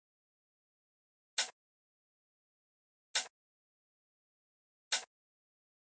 轻刀.ogg